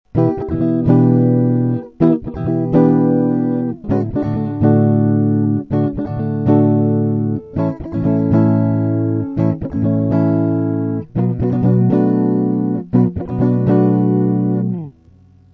mp3 - куплет